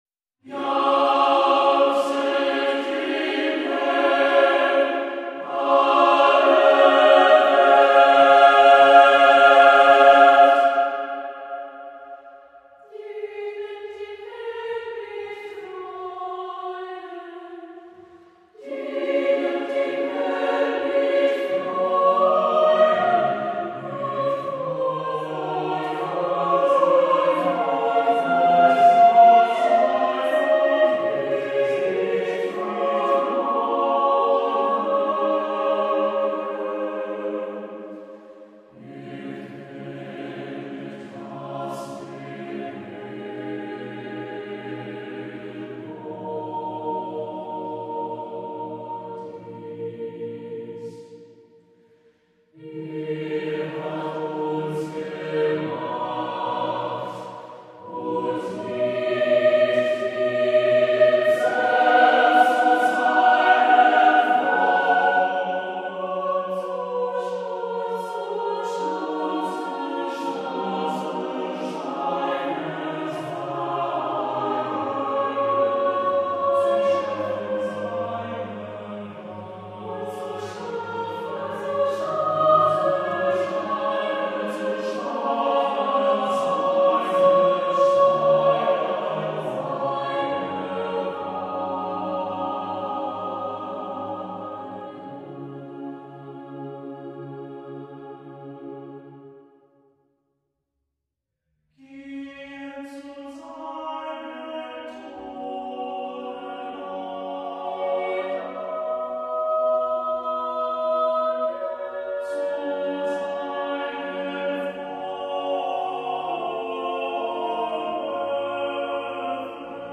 Music Category:      Choral